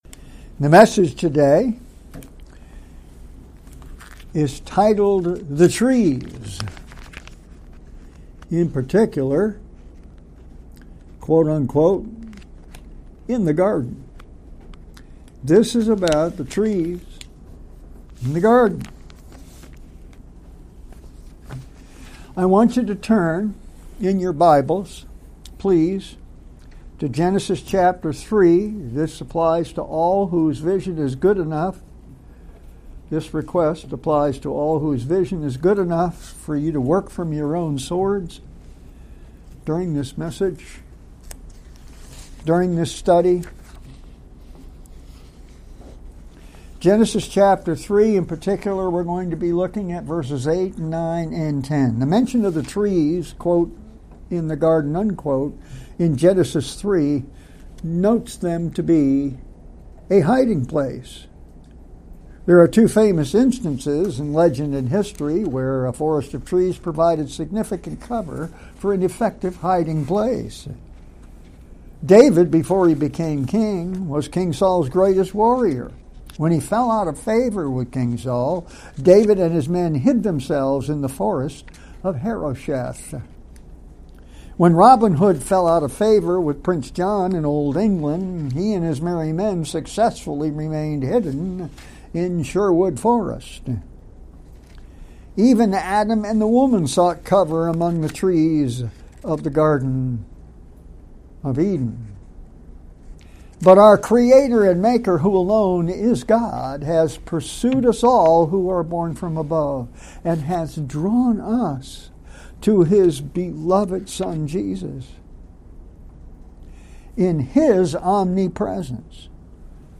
2025 Sermons Visiting Pastor Sermons